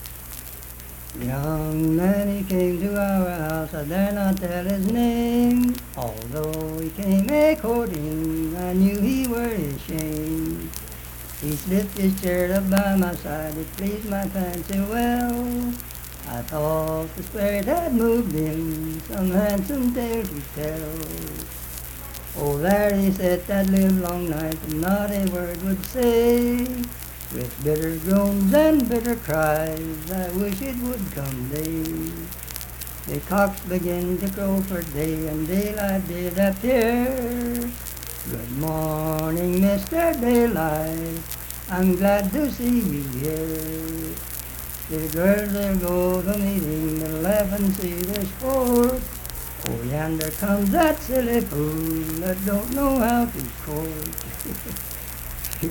Unaccompanied vocal music
Verse-refrain, 5(4).
Voice (sung)
Harts (W. Va.), Lincoln County (W. Va.)